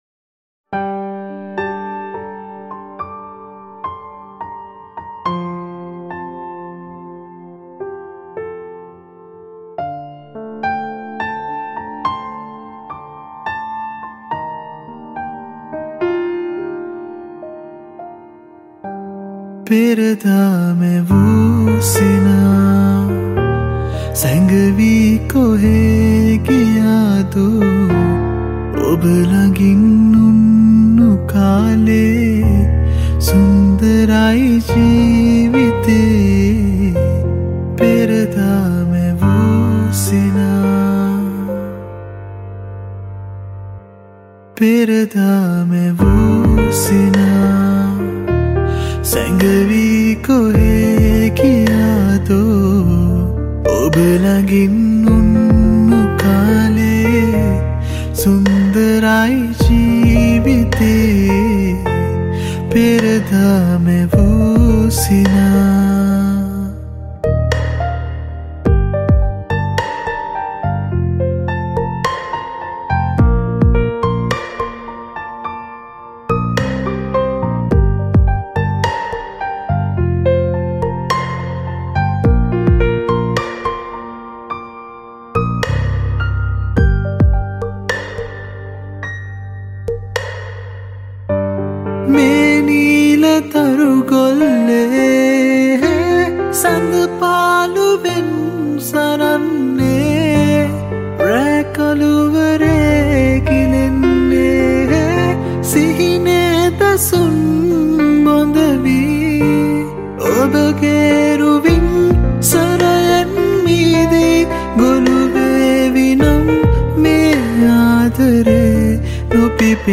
Cover Music